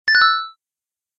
star-pickup.ogg